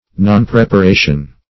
Search Result for " nonpreparation" : The Collaborative International Dictionary of English v.0.48: Nonpreparation \Non*prep`a*ra"tion\, n. Neglect or failure to prepare; want of preparation.
nonpreparation.mp3